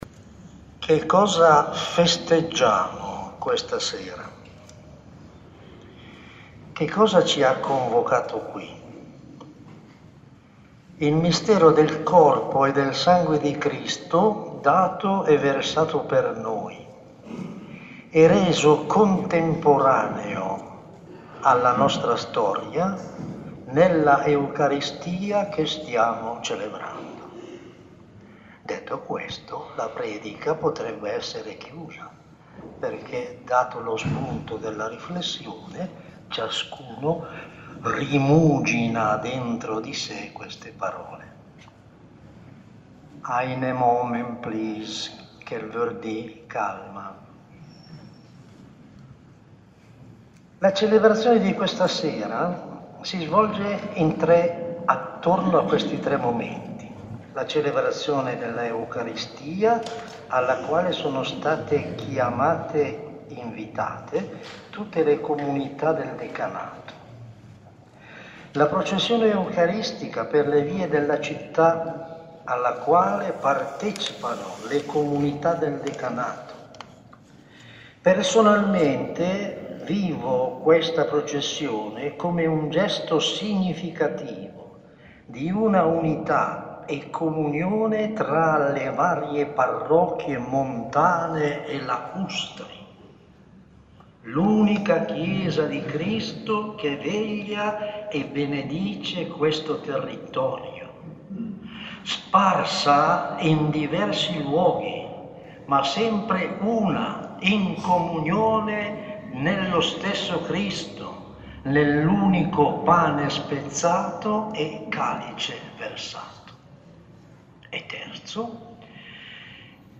Omelia Corpus Domini 2018
Categoria: Omelie
Omelia della Celebrazione Eucaristica in occasione della solennità del Corpus Domini celebrata a Livello decanale in Luino (